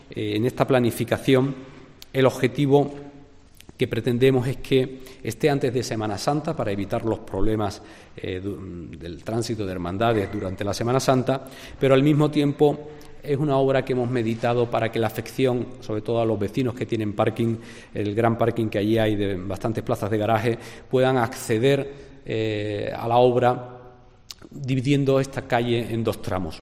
Felipe Arias, portavoz del equipo de Gobierno en el Ayuntamiento de Huelva